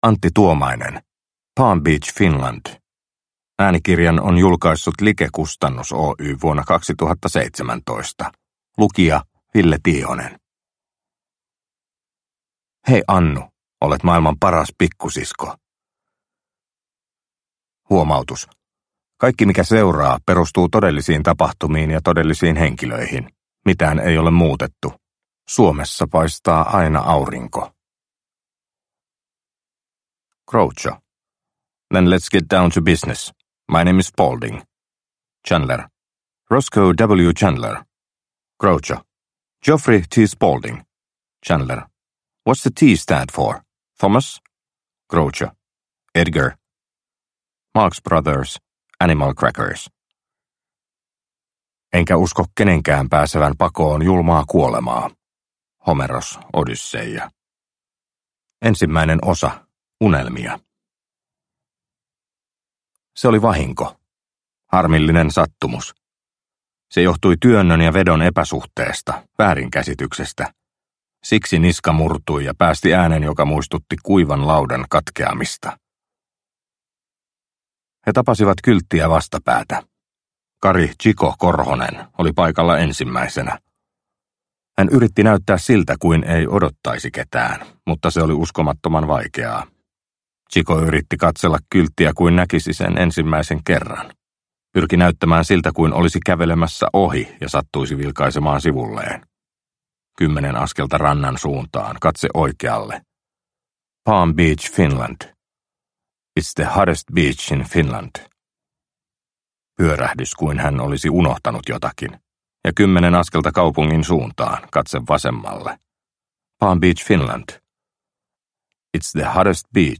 Palm Beach Finland – Ljudbok – Laddas ner